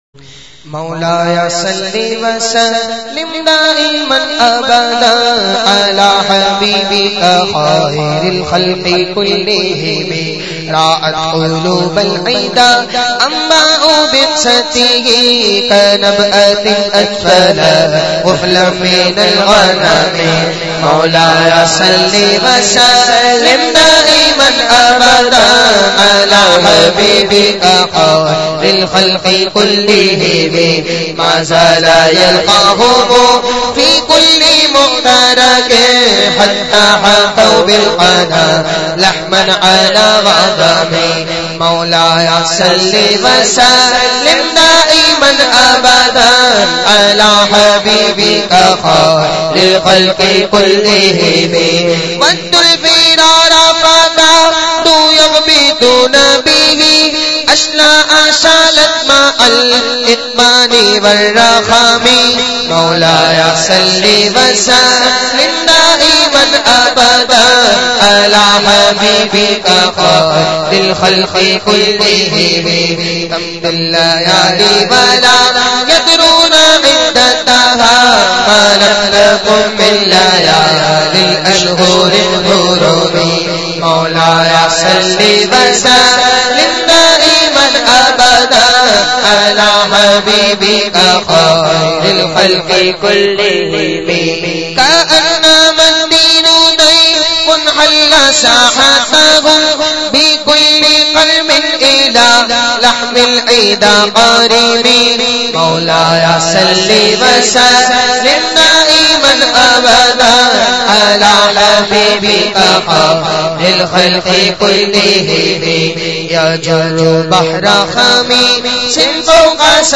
recited by famous Naat Khawan